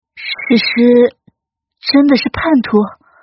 分段配音